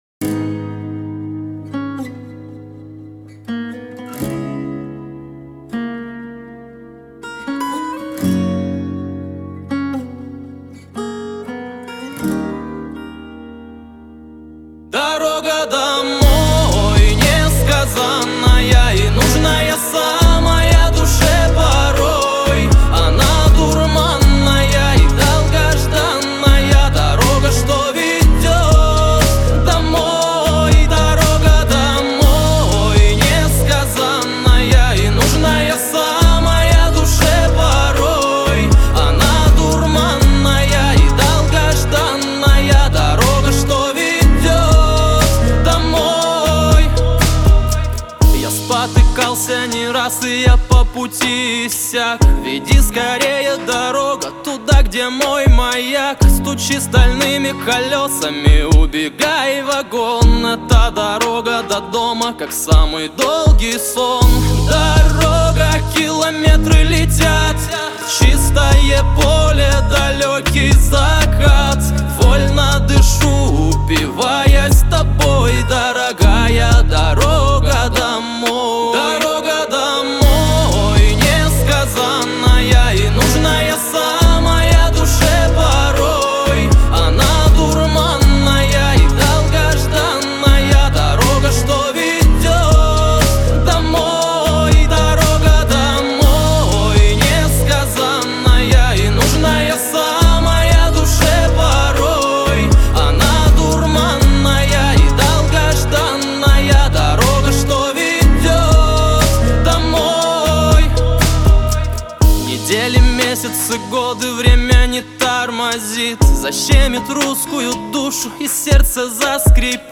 Новая музыка 2022РусскаяРусский Поп